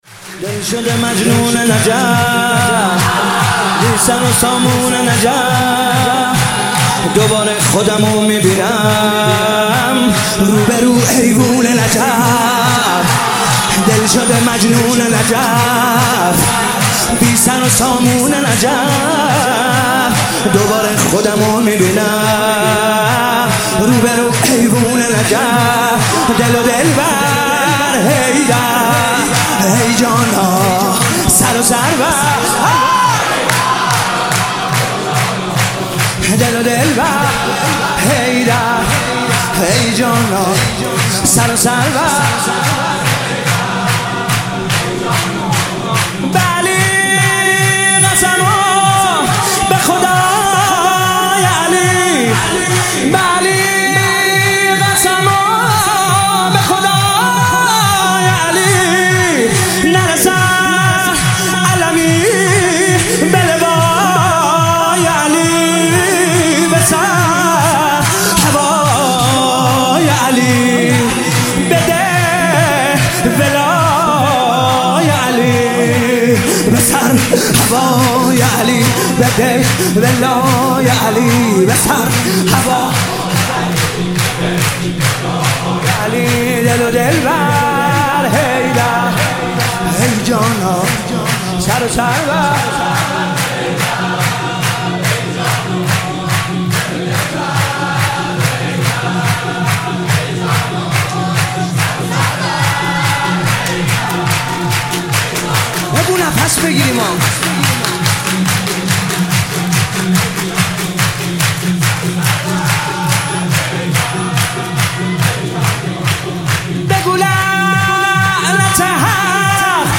سرود جدید